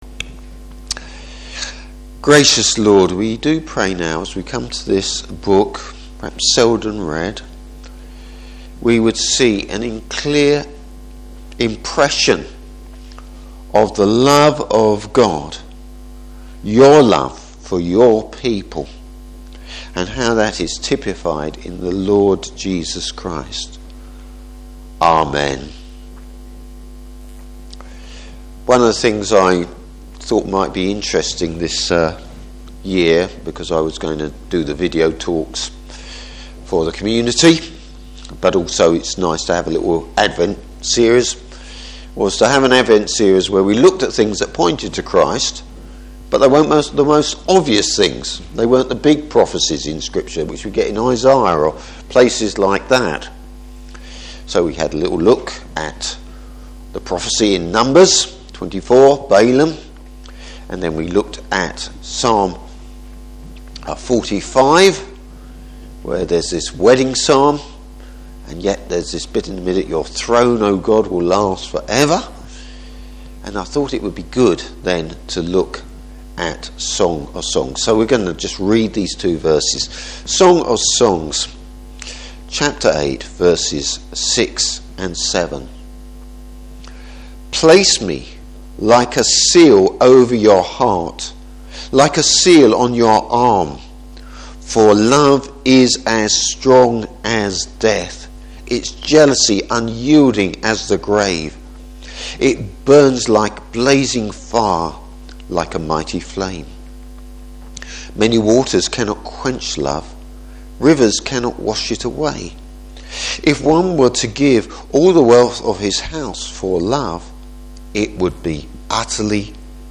Service Type: Morning Service The Lord’s love for His people.